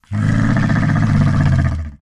Minecraft Version Minecraft Version 1.21.4 Latest Release | Latest Snapshot 1.21.4 / assets / minecraft / sounds / mob / camel / ambient3.ogg Compare With Compare With Latest Release | Latest Snapshot